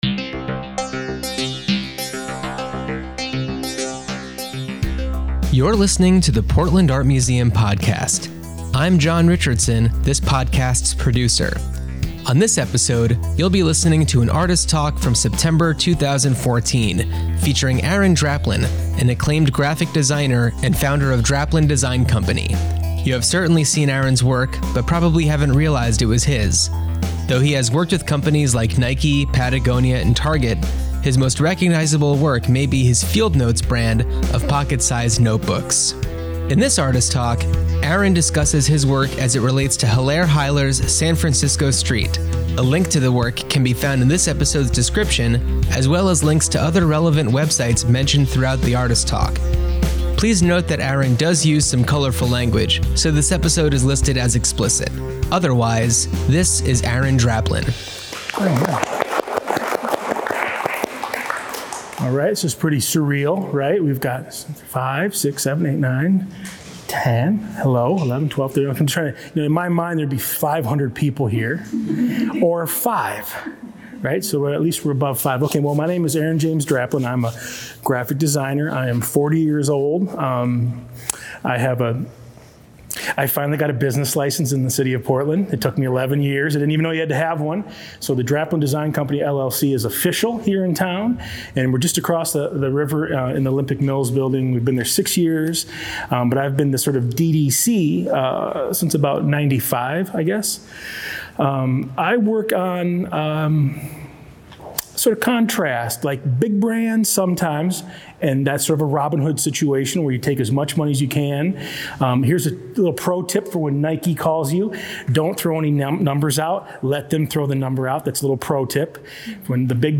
This episode of the Portland Art Museum Podcast digs into the archives to an Artist Talk by Aaron Draplin, a Portland-based graphic designer whose reputation expands far beyond Oregon.